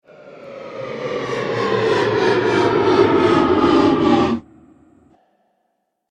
جلوه های صوتی
دانلود صدای کشتی 11 از ساعد نیوز با لینک مستقیم و کیفیت بالا